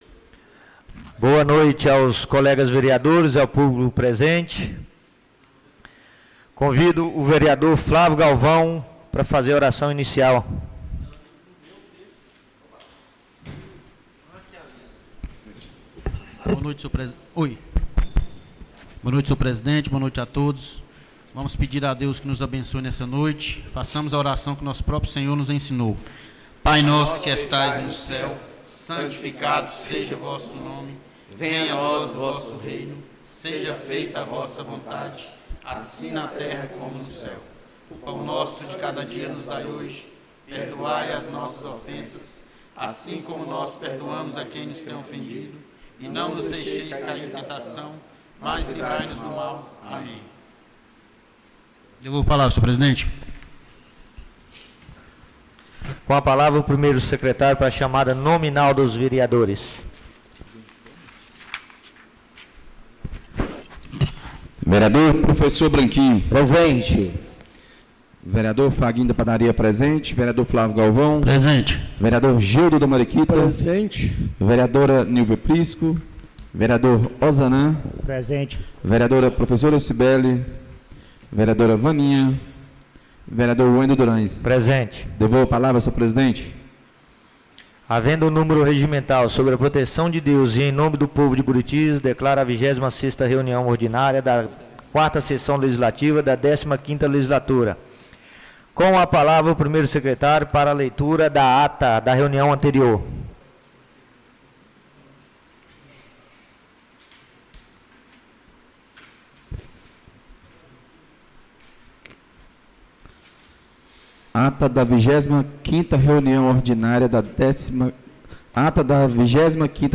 26ª Reunião Ordinária da 4ª Sessão Legislativa da 15ª Legislatura - 02-09-24